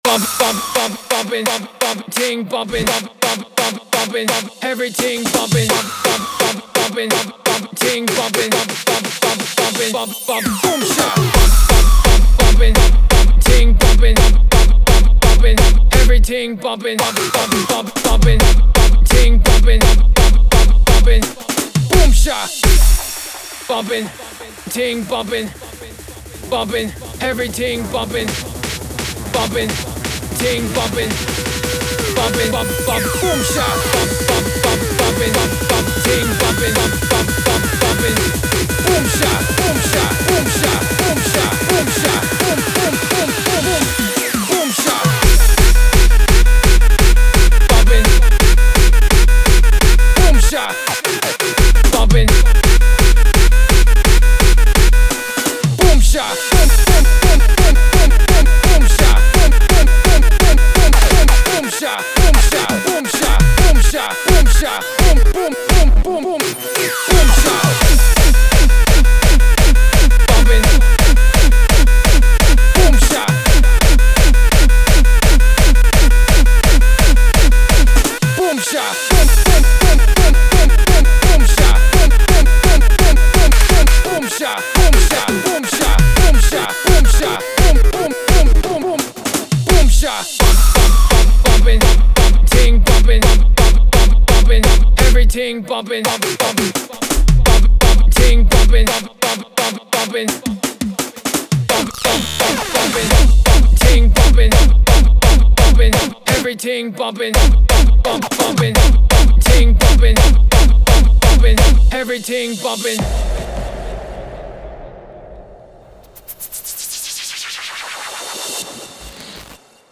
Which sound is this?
Género: Newstyle.